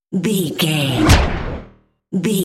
Creature dramatic hit
Sound Effects
Atonal
heavy
intense
dark
aggressive